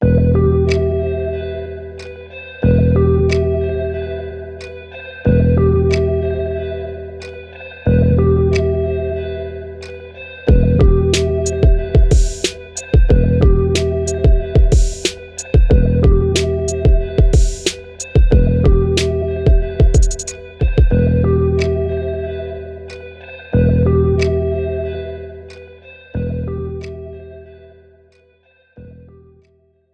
Professional Karaoke Backing Track.